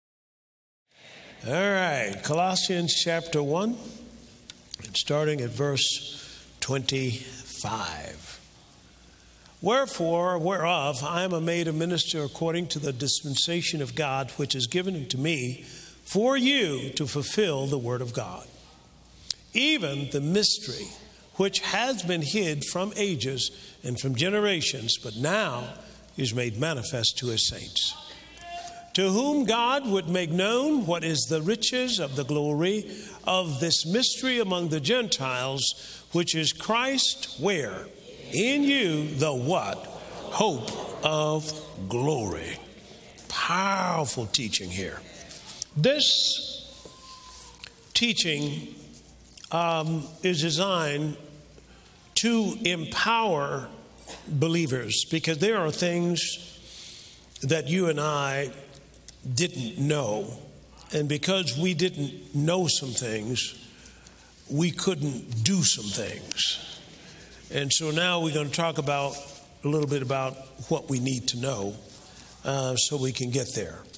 (4 Teachings) In these last days, it is critical to understand that the power of God has been bestowed upon you, to walk upright and be victorious.